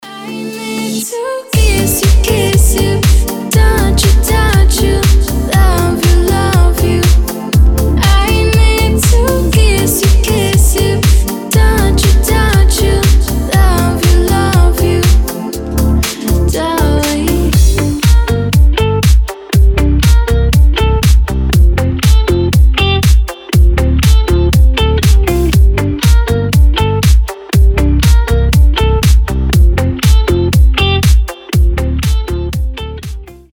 • Качество: 320, Stereo
ритмичные
красивые
женский голос
Dance Pop